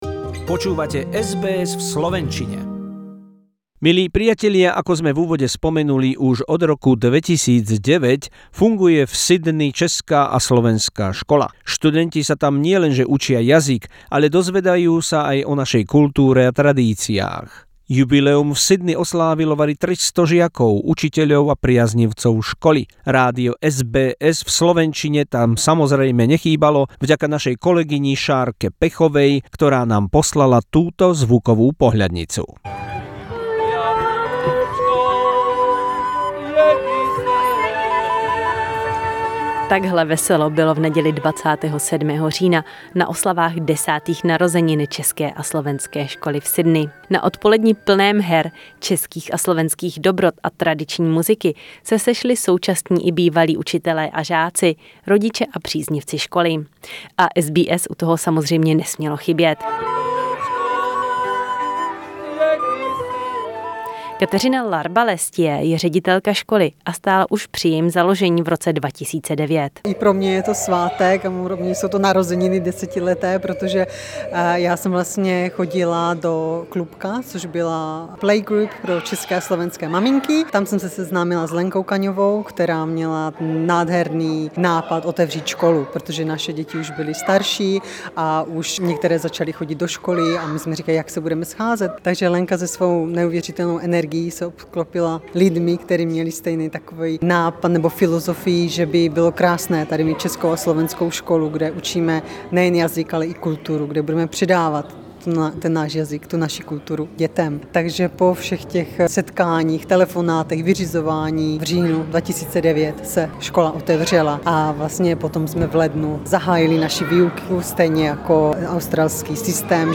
Zvuková pohľadnica z osláv 10. výročia Českej a Slovenskej školy v Sydney